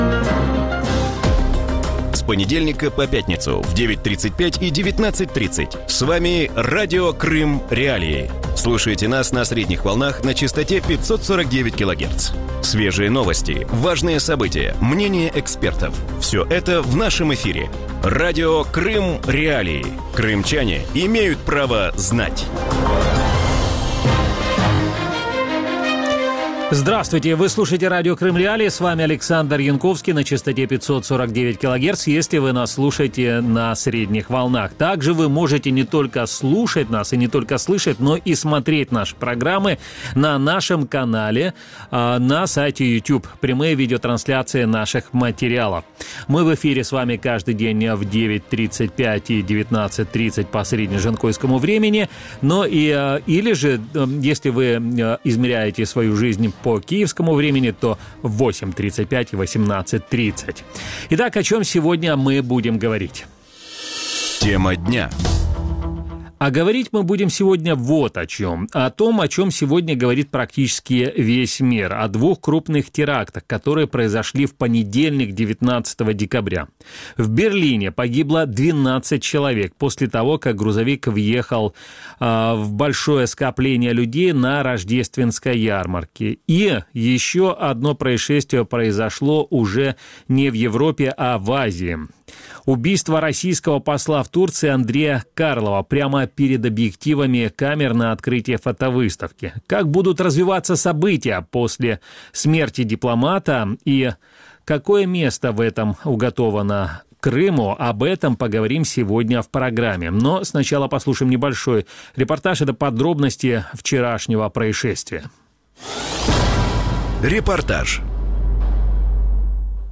У вечірньому ефірі Радіо Крим.Реалії говорять про вбивство російського посла в Туреччині Андрія Карлова. За що був убитий російський дипломат, до яких наслідків може призвести ця подія і як будуть розвиватися російсько-турецькі відносини?